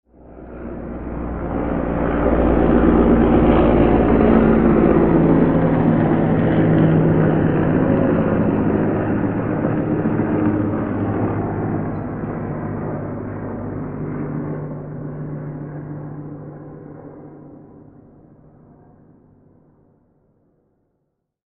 Helicopter-flying-overhead-and-passing-by-sound-effect.mp3